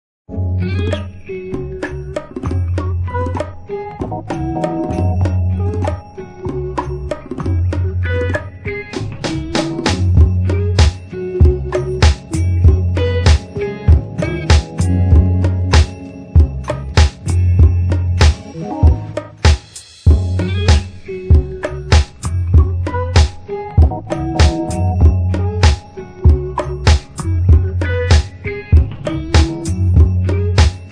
9 Styl: Hip-Hop Rok